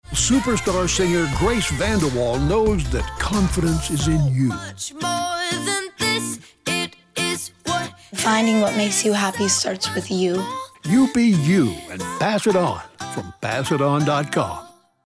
Listen to our new radio ad featuring Grace VanderWaal who believes your goal should be to find something that’ll make you happy.